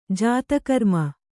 ♪ jāta karma